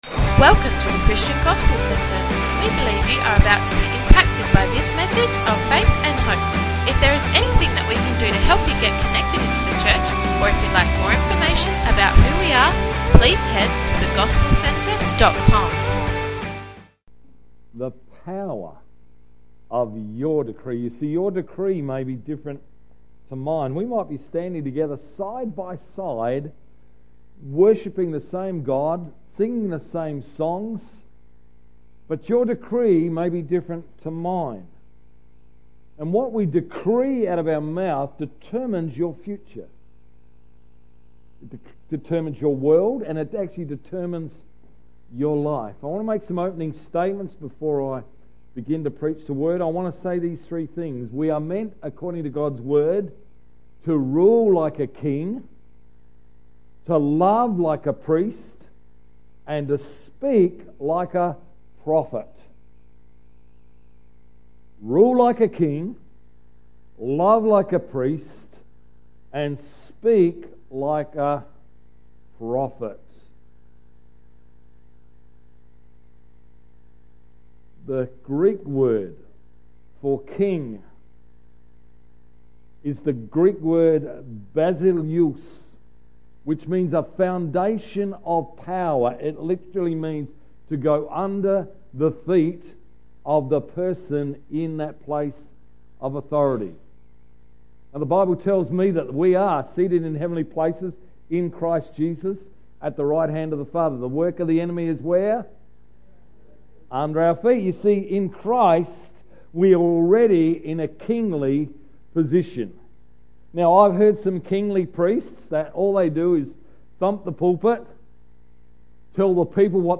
28th February 2016 – Evening Service